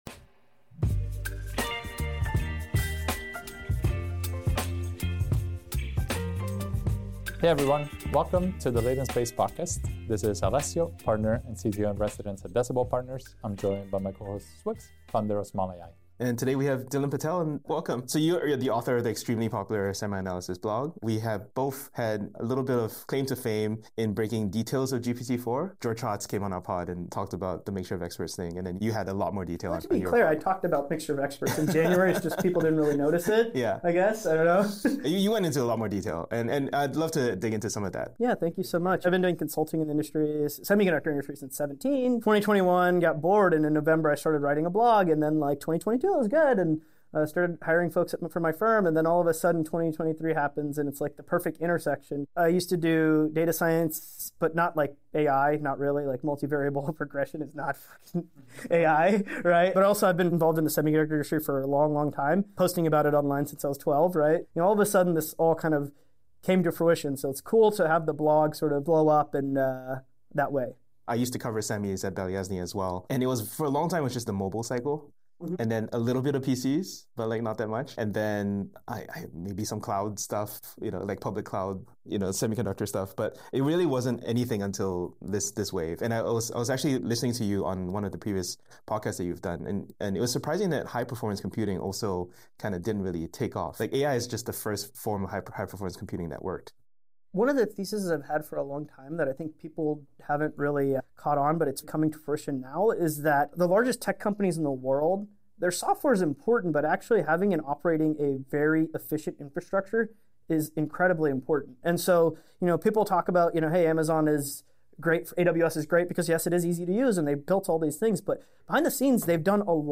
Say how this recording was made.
you might notice some small audio issues in some segments, we apologize.